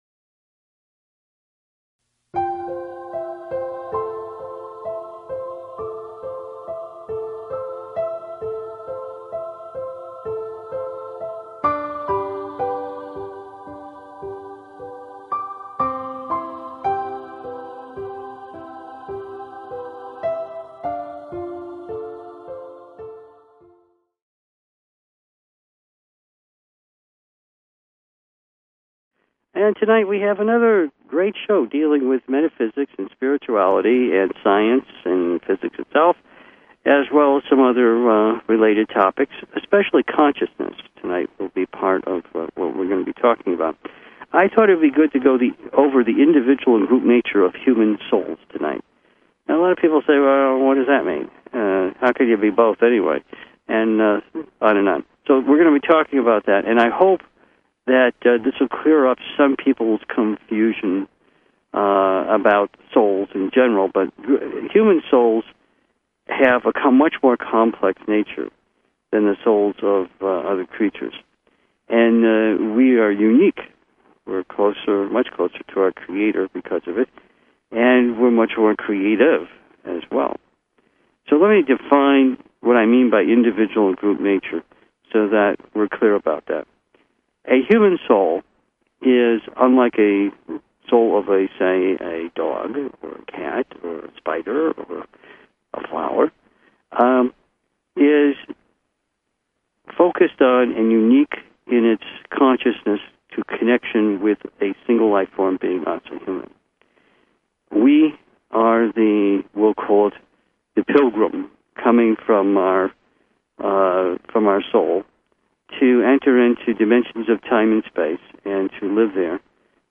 Subscribe Talk Show Why Life Is...